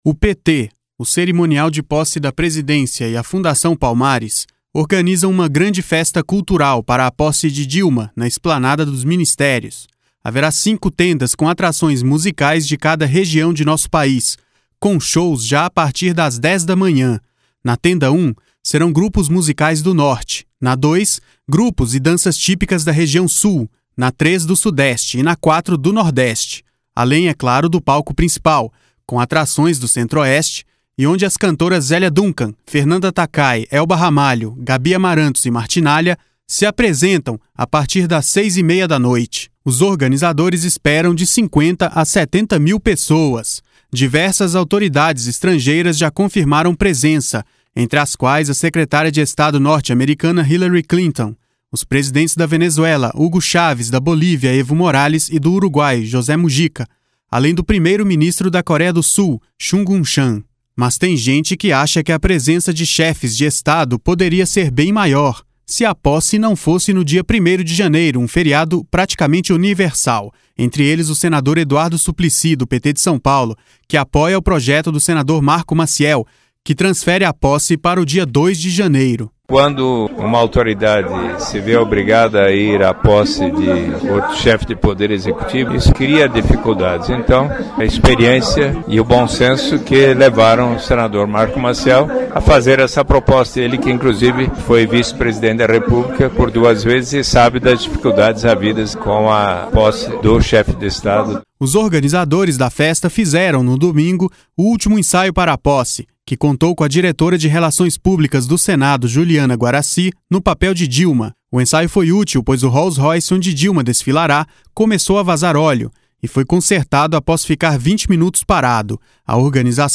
Rádio Senado
(EDUARDO SUPLICY): Quando uma autoridade se vê obrigada a ir à posse de outro chefe de Poder Executivo, isto cria dificuldades.